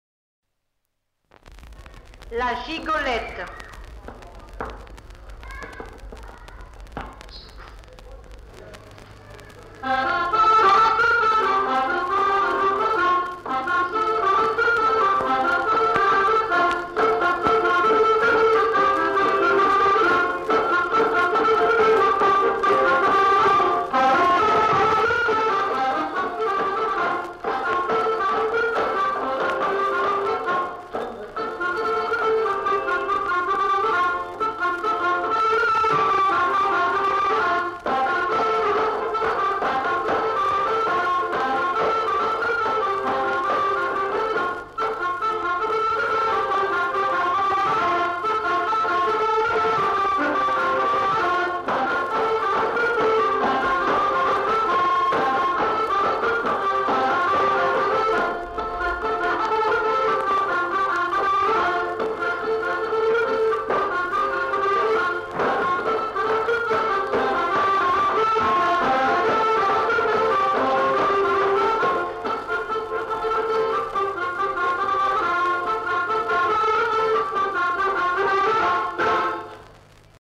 Aire culturelle : Couserans
Genre : morceau instrumental
Instrument de musique : accordéon chromatique
Danse : gigue
Notes consultables : La dame qui annonce les morceaux n'est pas identifiée.